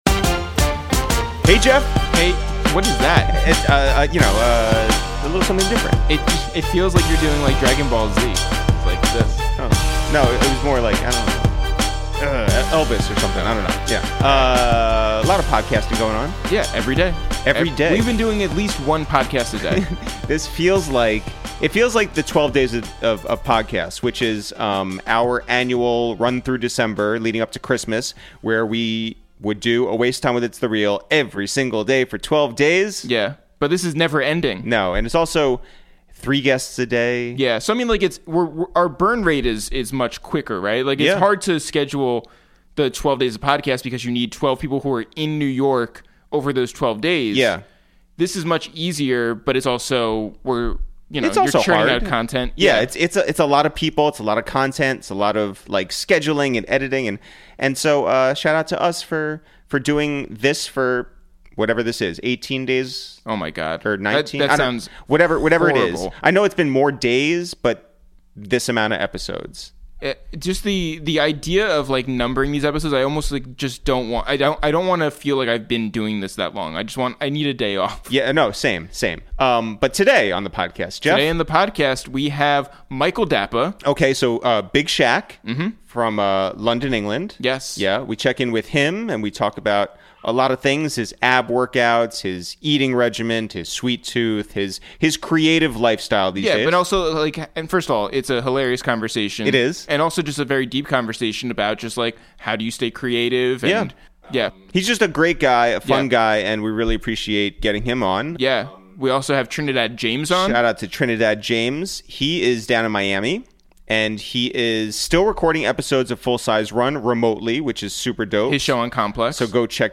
we make calls from our Upper West Side apartment